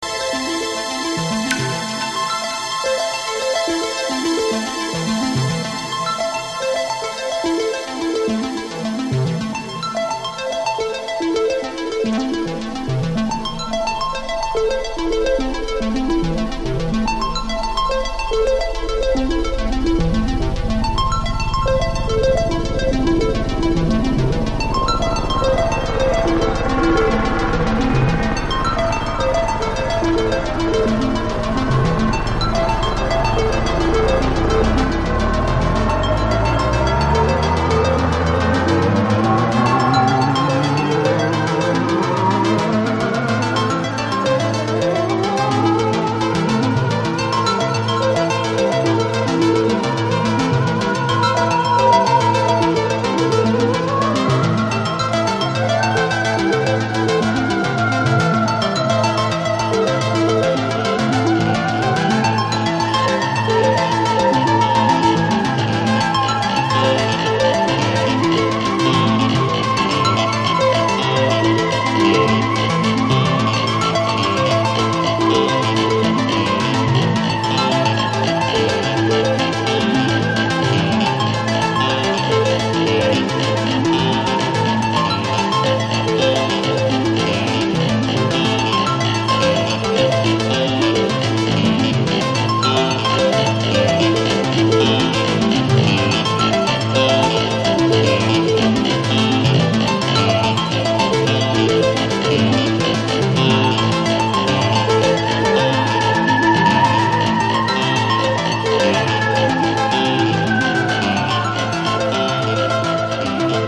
Жанр: Electronic, Berlin-School, Ambient
一个令人兴奋的专辑与深沉的气氛，带你进入奇异的梦境。
很适合做梦。